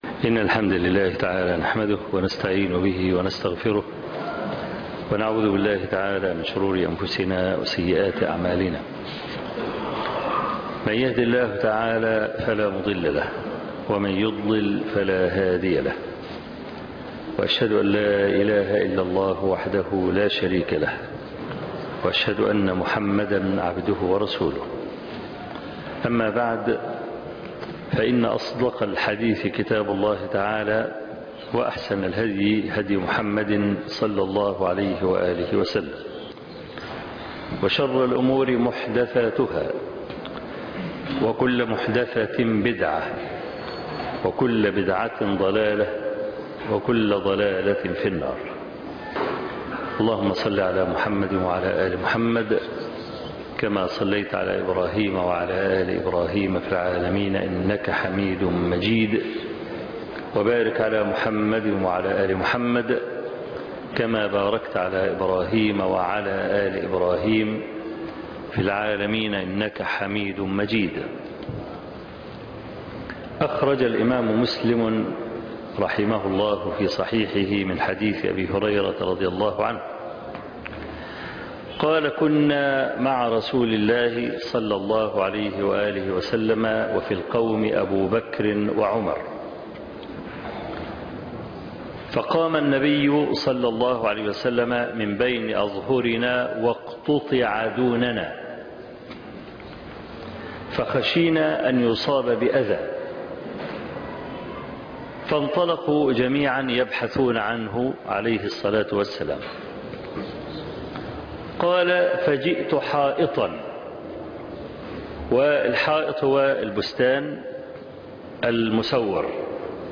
محبة النبي ﷺ بين الإتباع والابتداع محاضرة قيمة